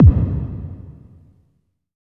30.07 KICK.wav